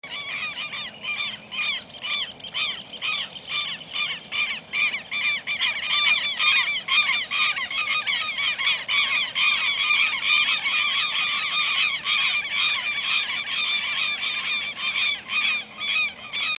Tero Común (Vanellus chilensis)
Nombre en inglés: Southern Lapwing
Localidad o área protegida: Reserva Natural del Pilar
Condición: Silvestre
Certeza: Observada, Vocalización Grabada